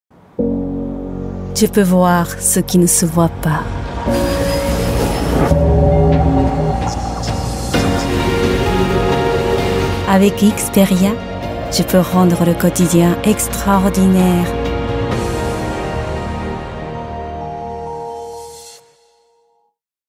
Voix off
Documentaire "Terre" - Yann Arthus-Bertrand
« Terra » de Yann Arthus-Bertrand, narration en japonais